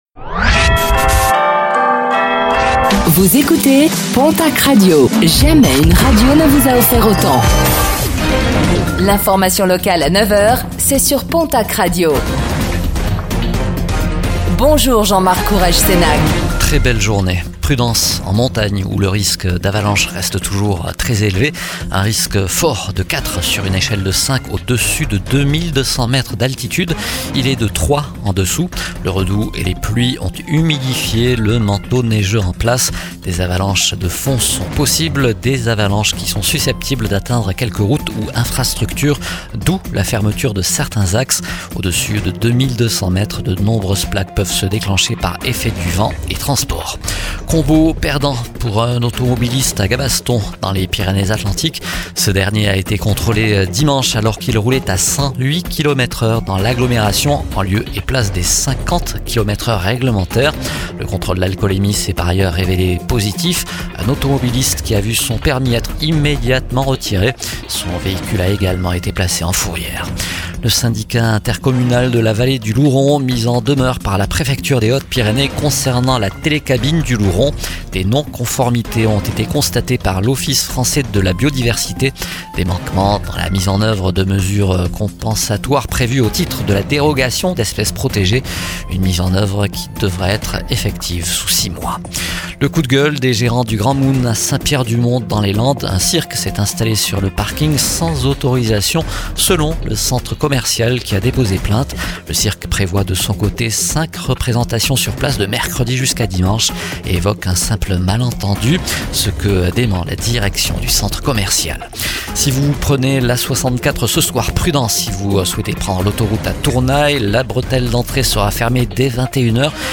Infos | Mardi 17 février 2026